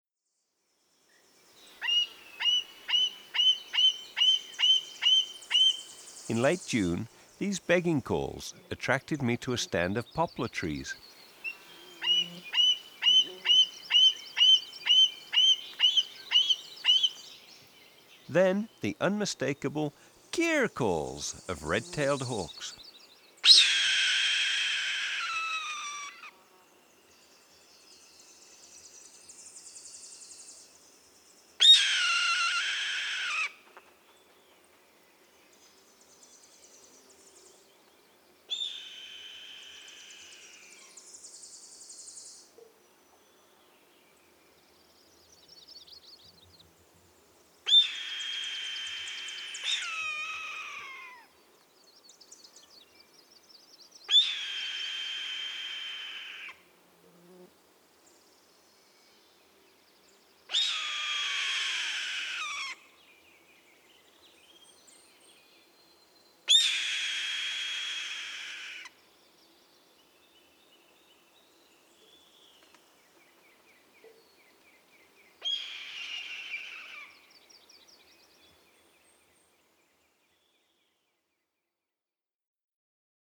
Red Tailed Hawk’s Song
66-red-tailed-hawk.m4a